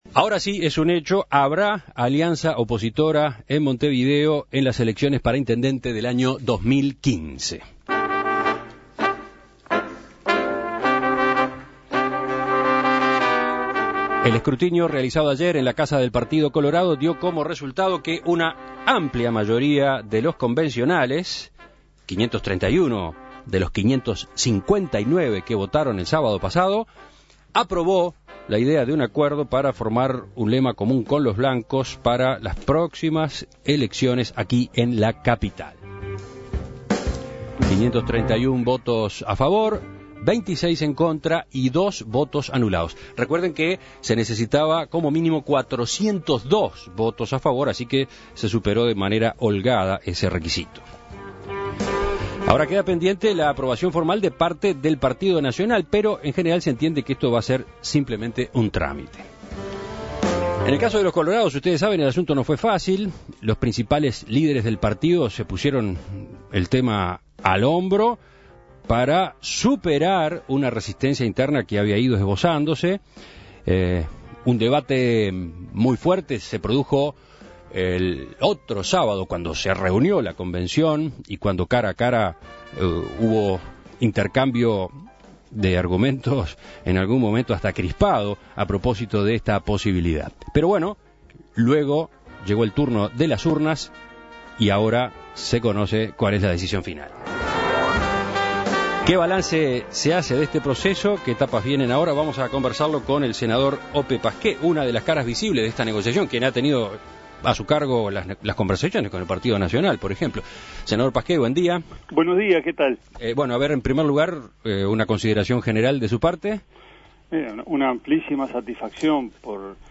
De los 559 convencionales colorados que votaron el acuerdo con el Partido Nacional (PN) para las elecciones de 2015 en Montevideo, se registró un total de 531 votos positivos, que superaron de manera holgada el mínimo de 402 requerido. Consultado por En Perspectiva, el senador de Vamos Uruguay Ope Pasquet manifestó su "amplísima satisfacción" por el resultado e indicó cuáles son los pasos a seguir a partir de ahora.
Entrevistas